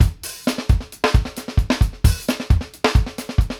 FUNK+GHOST-L.wav